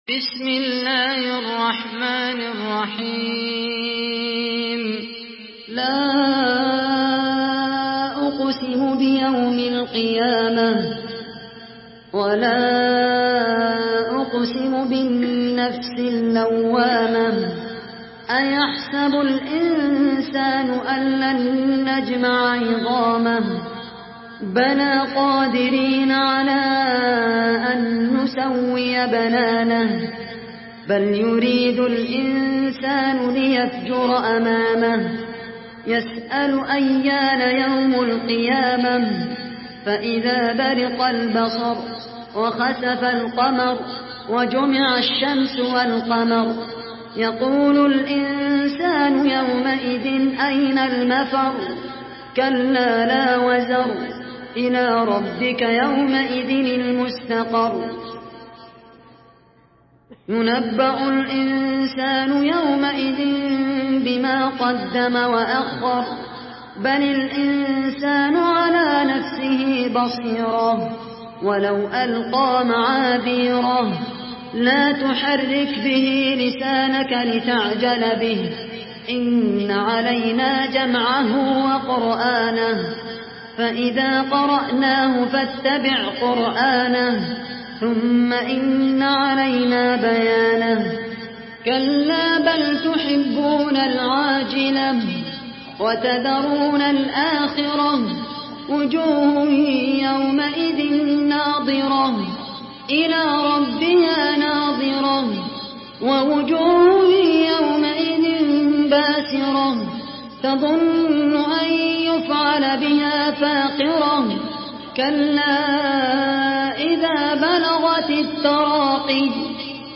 Khaled Al Qahtani mp3 Murattal Hafs An Asim